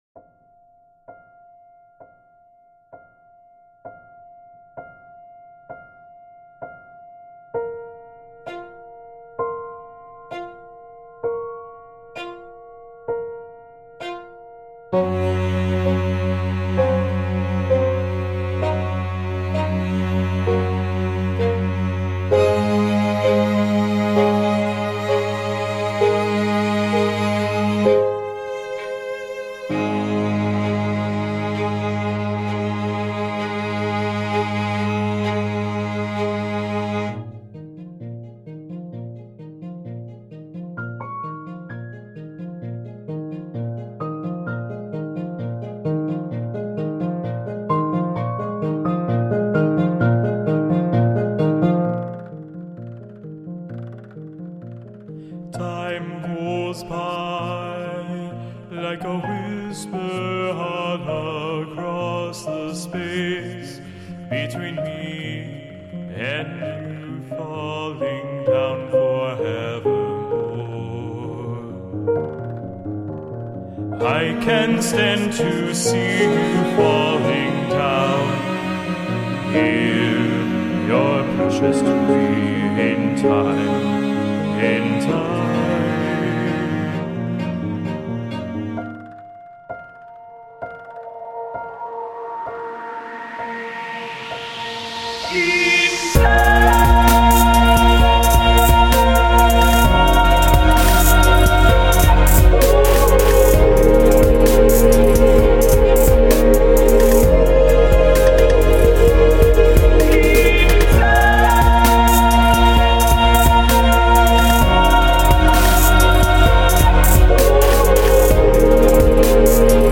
chill album